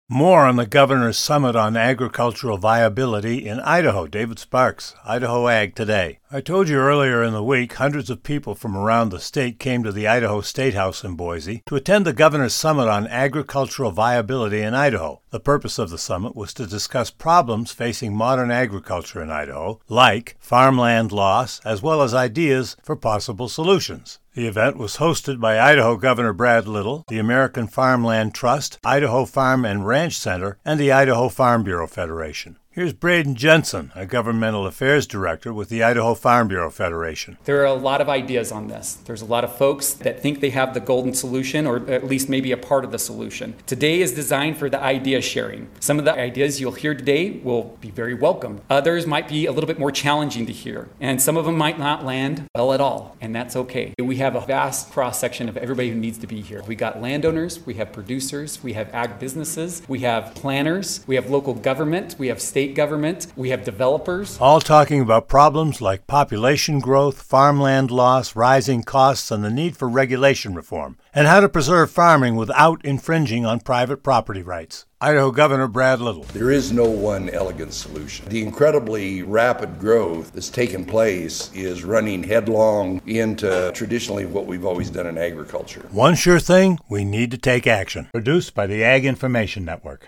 On December 18th hundreds of people from around the state came to the Idaho Statehouse in Boise to attend the Governor's Summit on Agricultural Viability in Idaho.
There were multiple speakers, panels, questions from the audience, and an interactive group survey that attendees took part in by giving answers to questions on their phones….